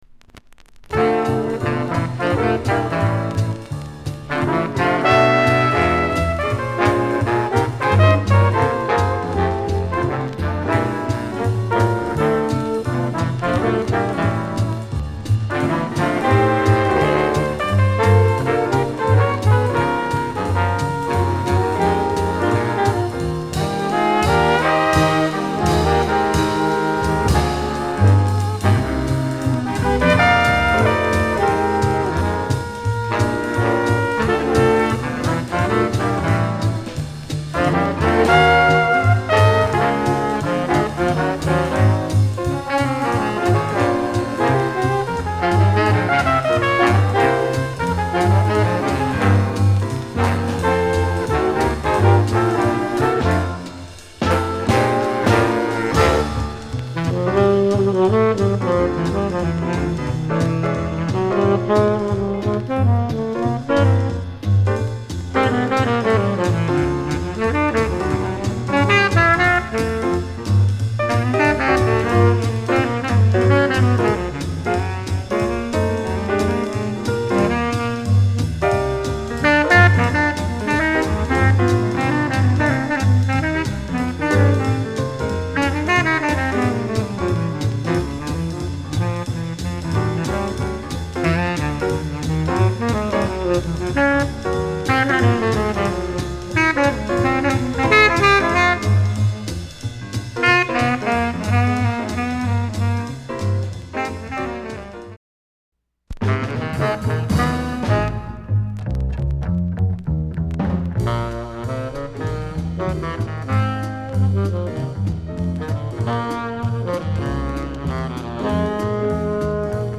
discription:Mono